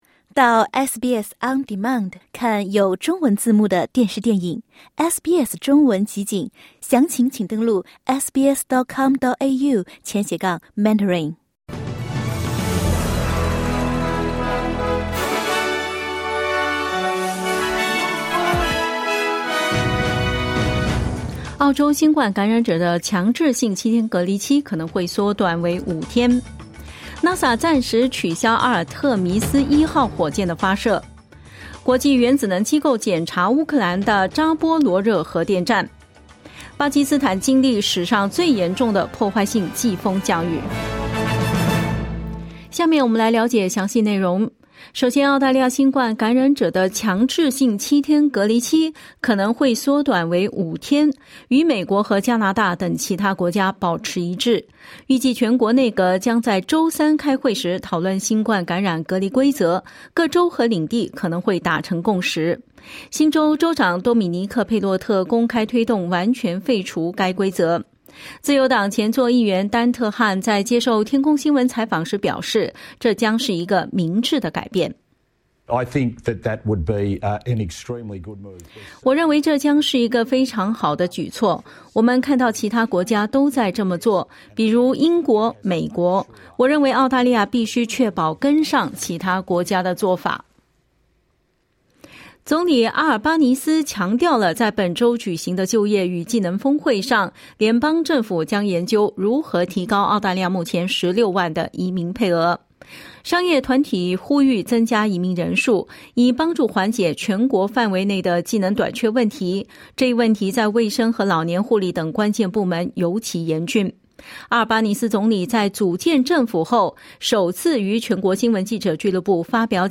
SBS早新闻（8月30日）
请点击收听SBS普通话为您带来的最新新闻内容。